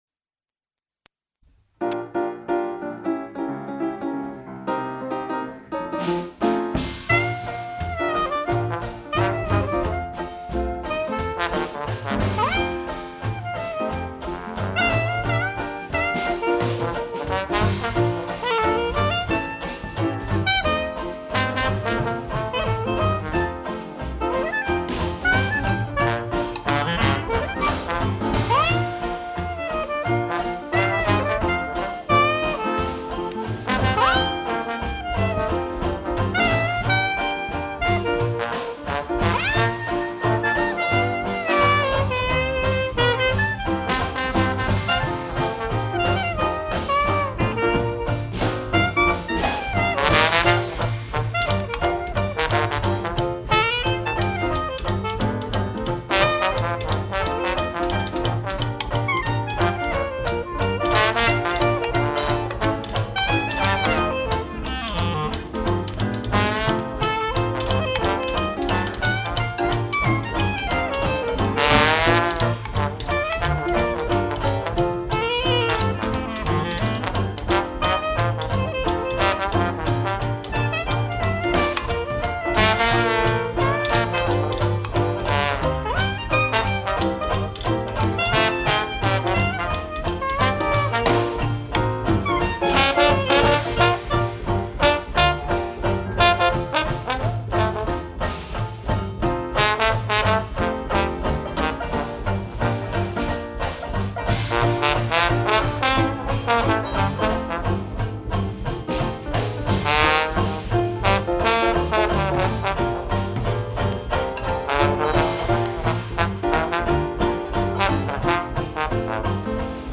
clarinet.